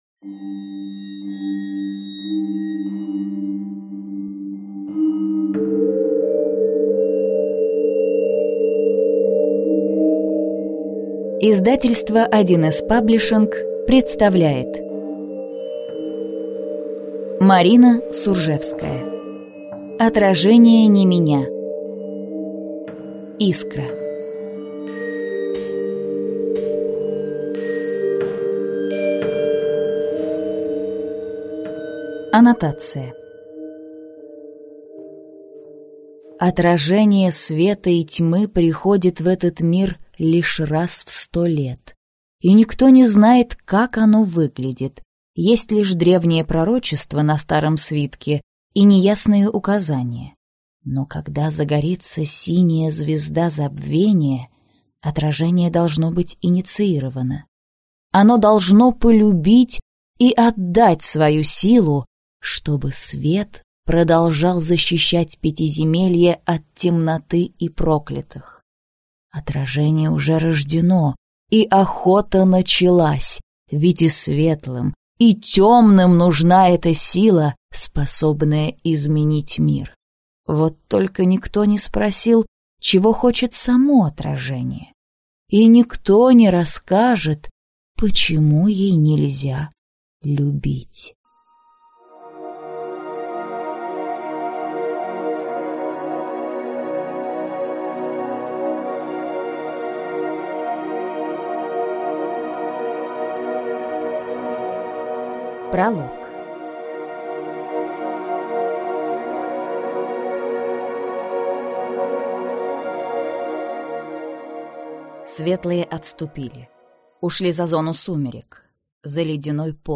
Аудиокнига Отражение не меня. Искра - купить, скачать и слушать онлайн | КнигоПоиск